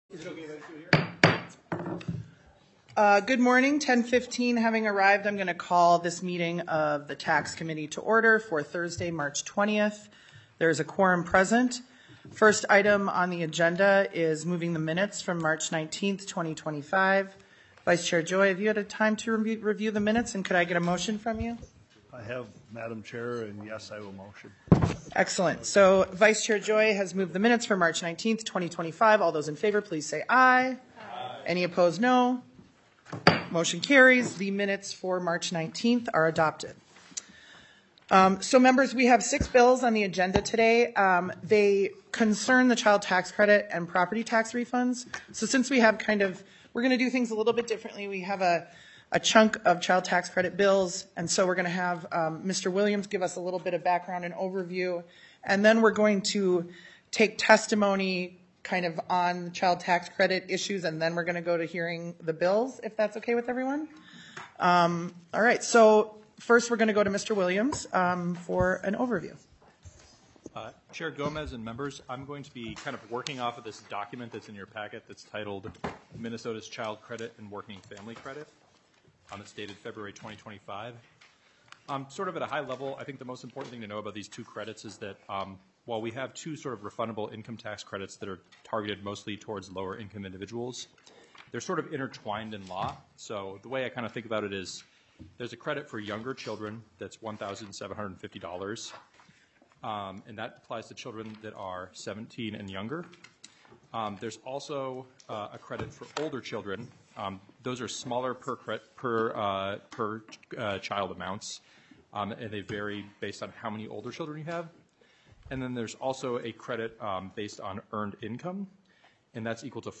04:45 - Public testimony on Child Tax Credit bills (HF2254, HF2502, HF2302). 19:27 - HF2254 (Hanson) Income tax; baby bonus established within the Minnesota child credit.